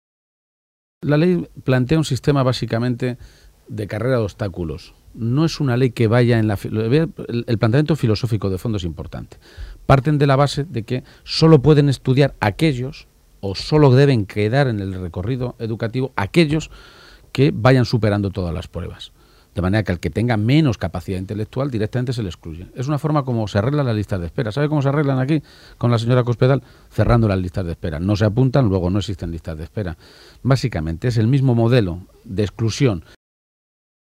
Emiliano García-Page durante la entrevista que se le ha realizado en la Cadena SER
Cortes de audio de la rueda de prensa